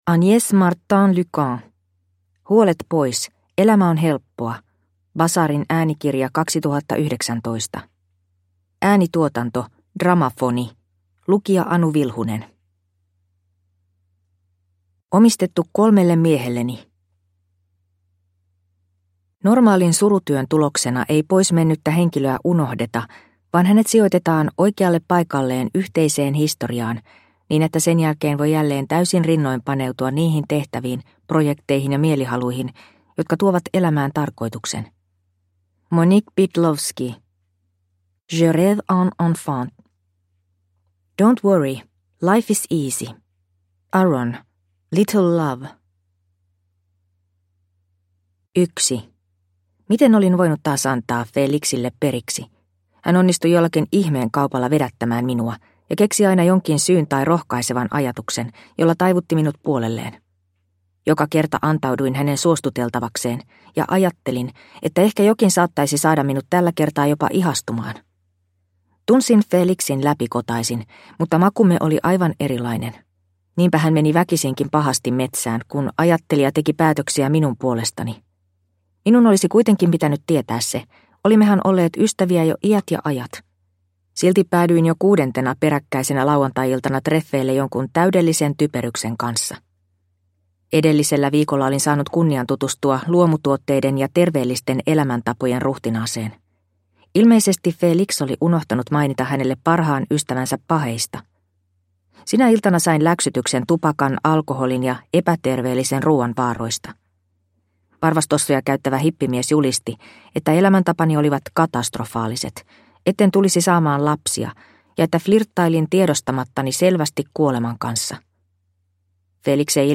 Huolet pois, elämä on helppoa – Ljudbok – Laddas ner